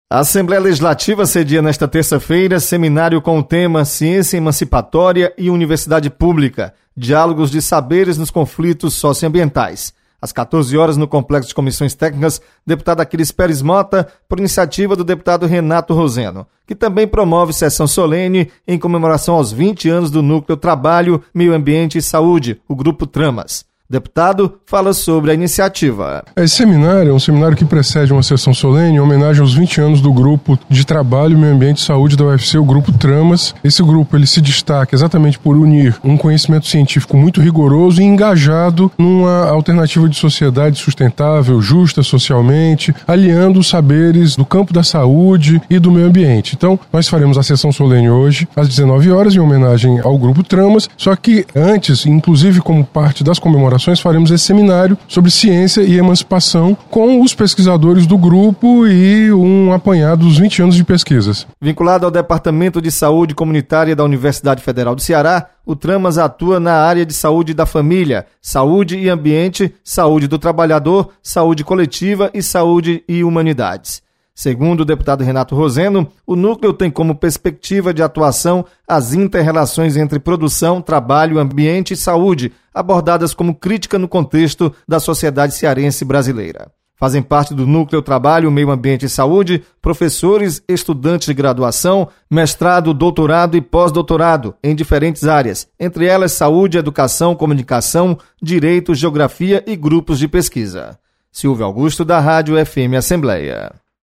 Você está aqui: Início Comunicação Rádio FM Assembleia Notícias Seminário